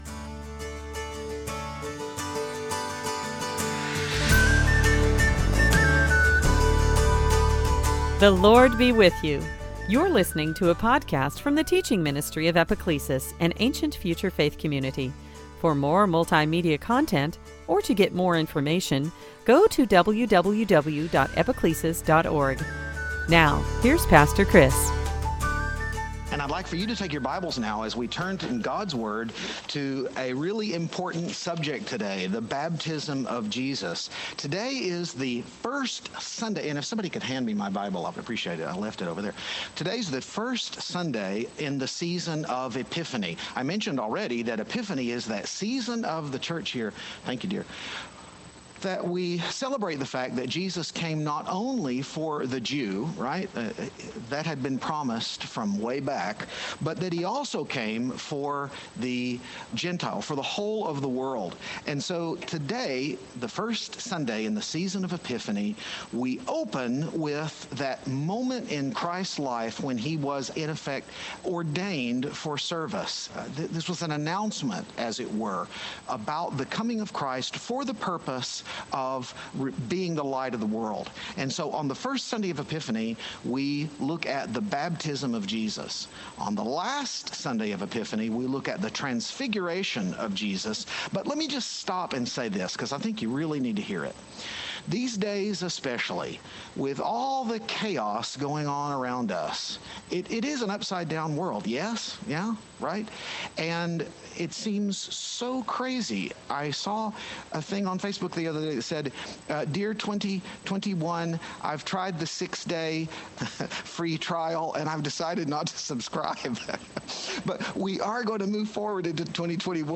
2021 Sunday Teaching Baptism of Christ creation New Noah sanctifying water Epiphany